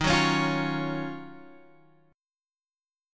D# Minor 9th